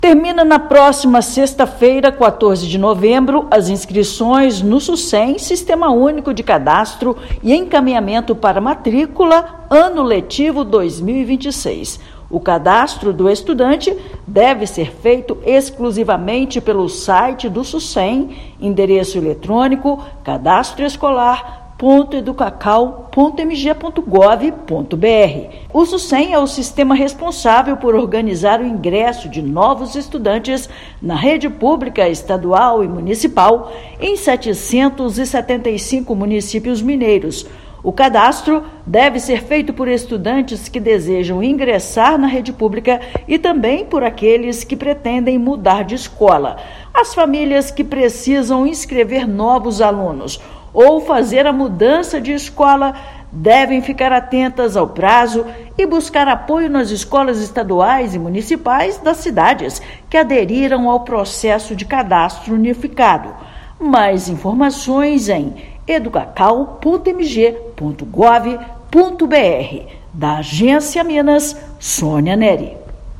[RÁDIO] Prazo para cadastro de novos estudantes e mudança de escola na rede pública de Minas Gerais termina nesta sexta-feira (14/11)
Famílias devem se inscrever no Sucem 2026, sistema que organiza as matrículas em unidades estaduais e municipais. Ouça matéria de rádio.